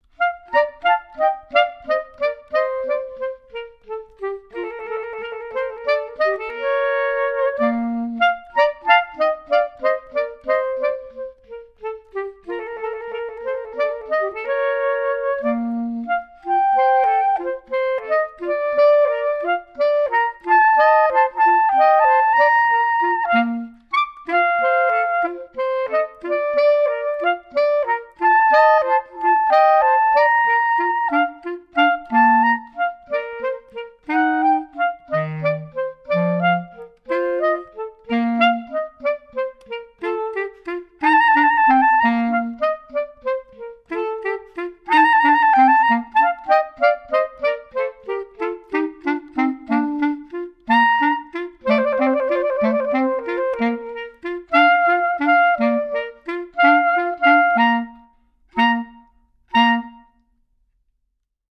Sight Reading
Recorded at home in Manhattan April 25, 2014
Bb clarinet
Stereo (Pro Tools)